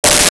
Sci Fi Beep 14
Sci Fi Beep 14.wav